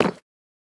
Minecraft Version Minecraft Version snapshot Latest Release | Latest Snapshot snapshot / assets / minecraft / sounds / block / nether_bricks / step5.ogg Compare With Compare With Latest Release | Latest Snapshot
step5.ogg